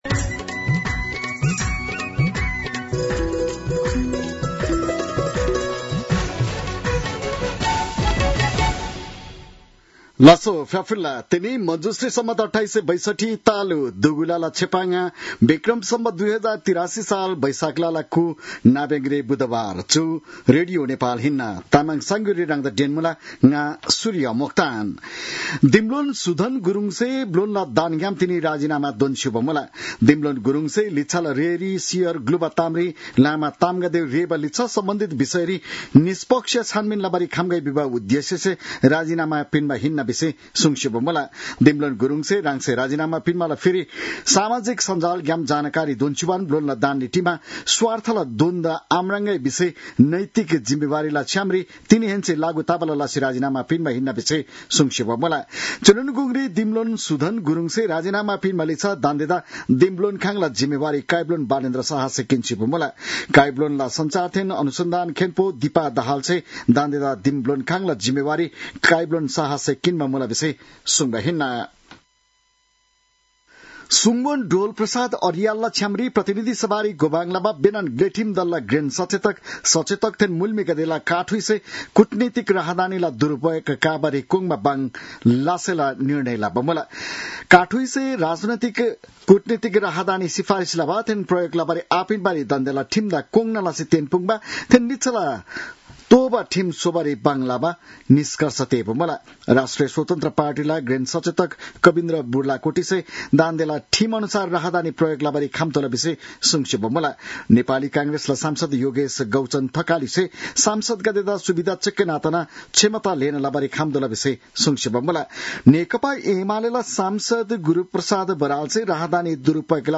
तामाङ भाषाको समाचार : ९ वैशाख , २०८३